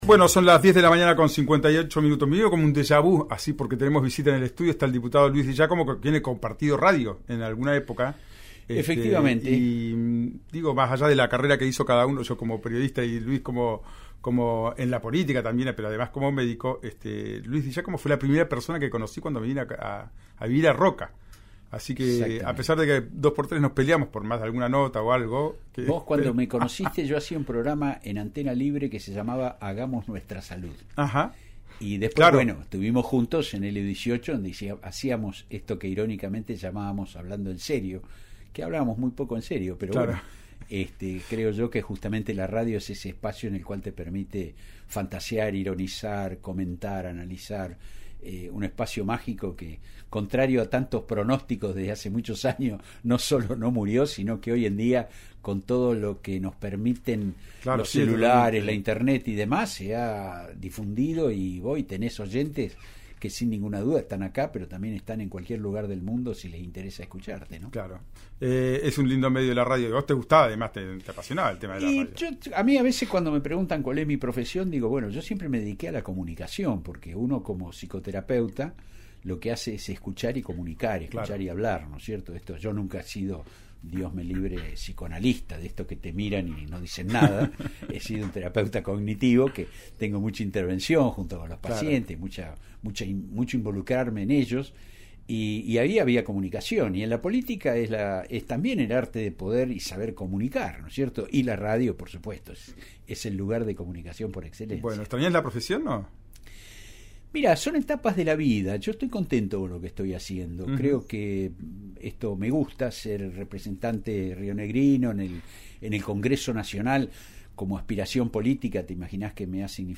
El dirigente estuvo esta mañana en los estudios de RIO NEGRO RADIO donde nuevamente exigió al gobierno Nacional dar marcha atrás con la concesión de las represas a Enarsa.
Durante la entrevista, Di Giácomo también fue consultado sobre la actividad de la gobernadora en San Carlos de Bariloche atento a que ya formalizó su intención de presentarse como candidata intendenta en esa ciudad.